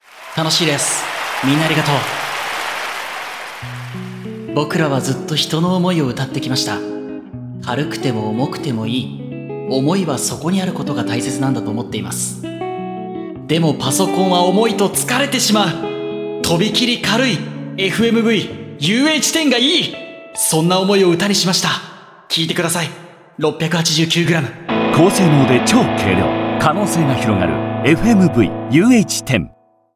フェスの臨場感を表現するため、空間的な立体感を強調し、没入感やワクワク感を演出しています。
Fujitsu_Live_Rockband_2406.mp3